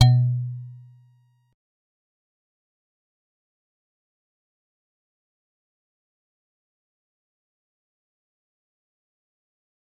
G_Musicbox-B2-pp.wav